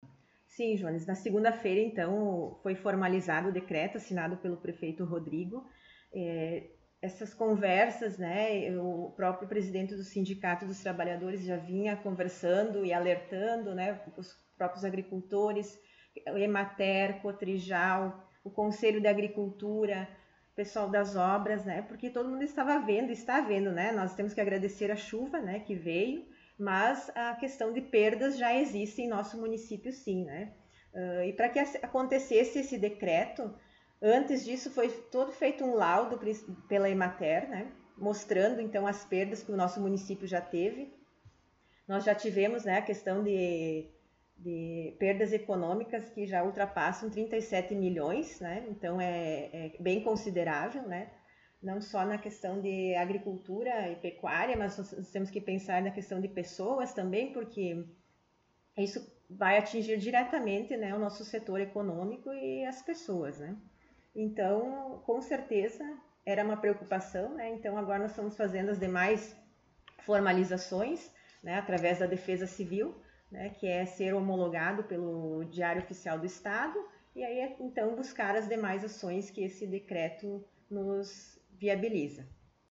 Secretária Municipal de Administração e Fazenda concedeu entrevista
O Colorado em Foco esteve na Prefeitura, na sala da secretária, para sabermos um pouco mais da situação econômica do município e outros assuntos.